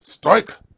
gutterball-3/Gutterball 3/Commentators/Master/zen_strike.wav at 7520ed7142fb48dbefad8fa9507bb2be29c89937
zen_strike.wav